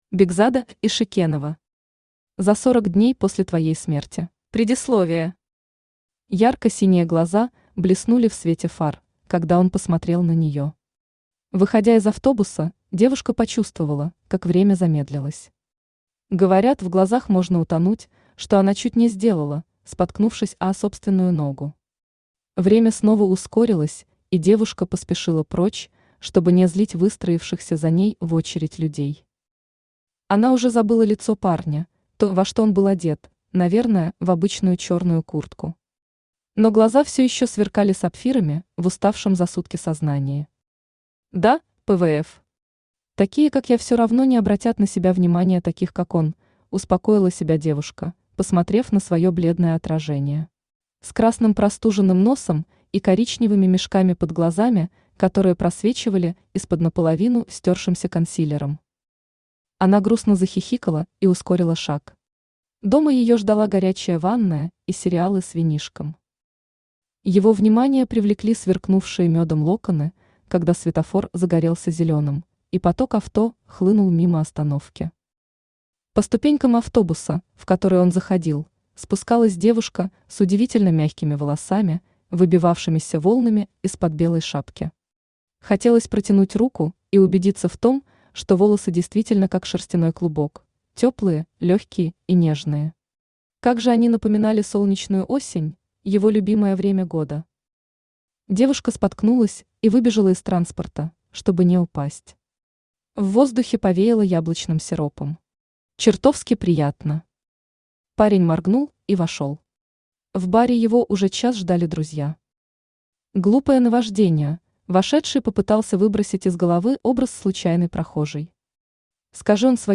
Аудиокнига За 40 дней после твоей смерти | Библиотека аудиокниг
Aудиокнига За 40 дней после твоей смерти Автор Бекзада Маликовна Ишекенова Читает аудиокнигу Авточтец ЛитРес.